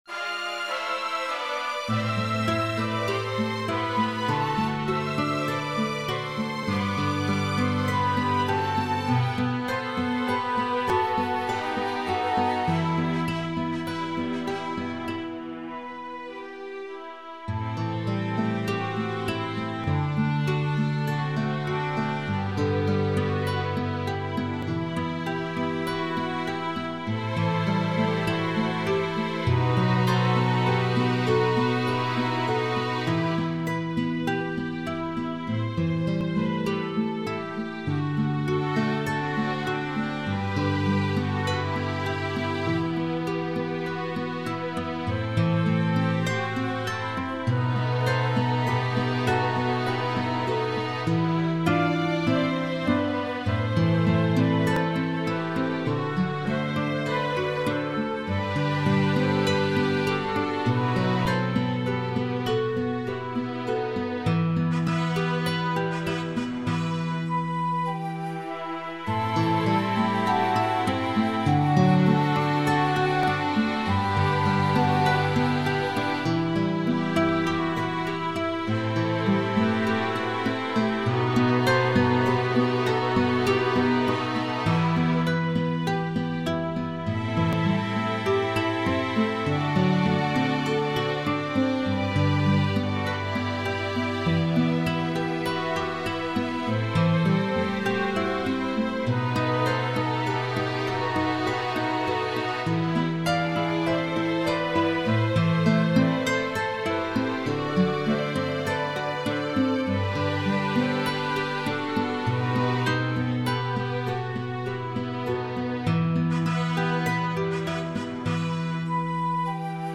Описание: Современная электронная версия без слов.